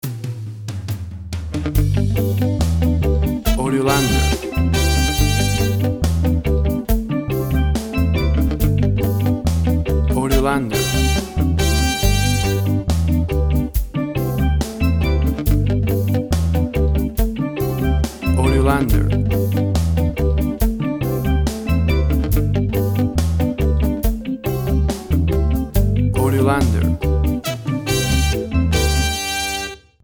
WAV Sample Rate 16-Bit Stereo, 44.1 kHz
Tempo (BPM) 70